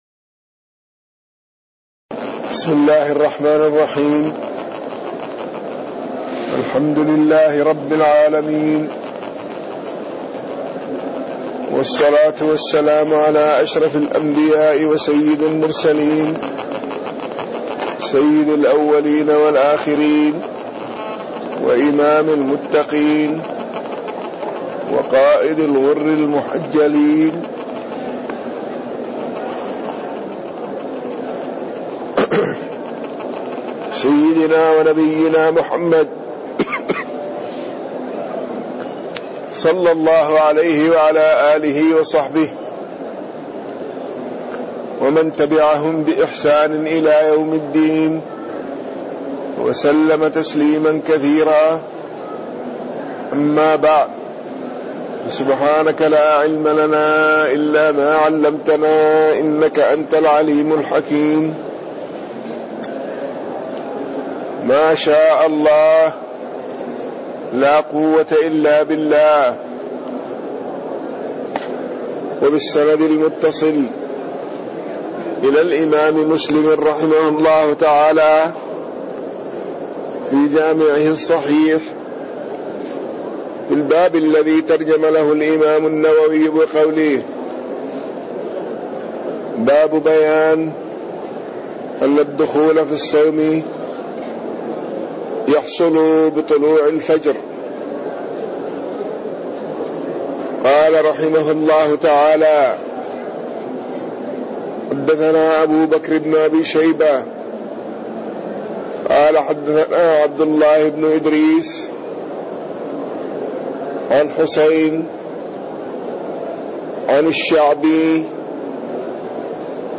تاريخ النشر ٢٩ صفر ١٤٣٣ هـ المكان: المسجد النبوي الشيخ